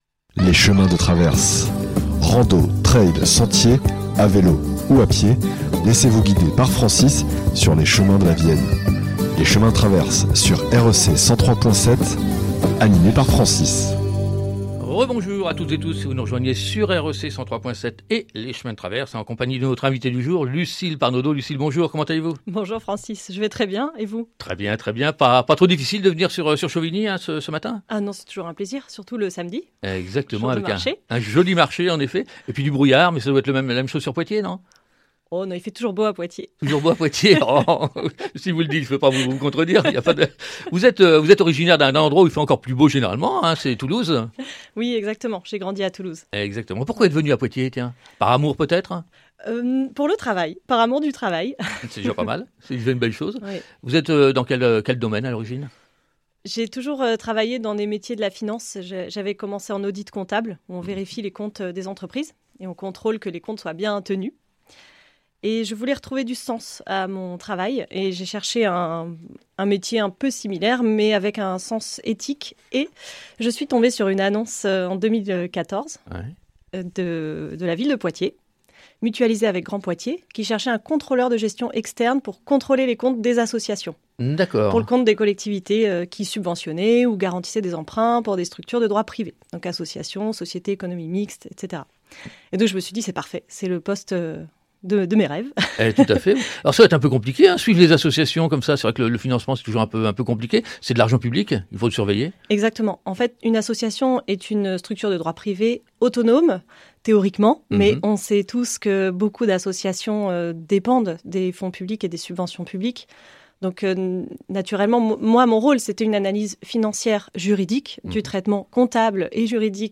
Les chemins de traverses – Rencontre avec Lucile Parnaudeau – 11 octobre 2025